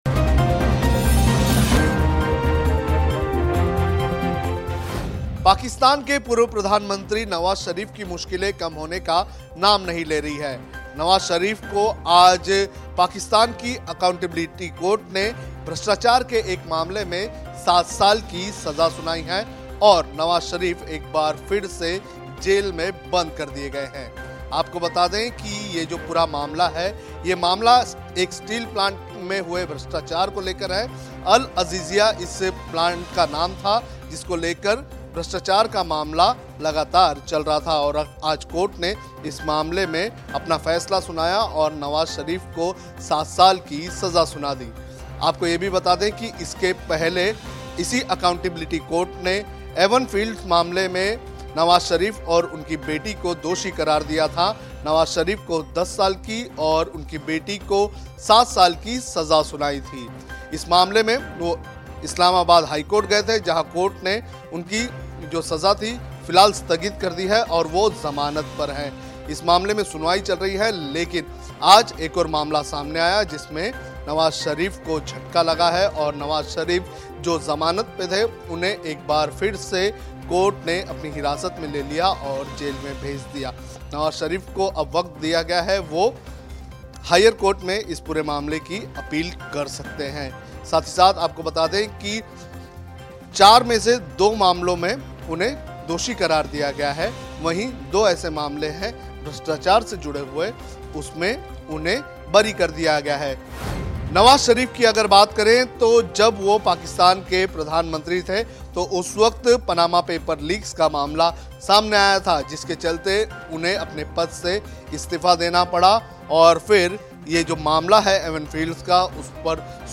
न्यूज़ रिपोर्ट - News Report Hindi / पाकिस्तान : नवाज़ शरीफ फिर एक बार जेल में बंद, भ्रष्टाचार के एक और आरोप में दोषी करार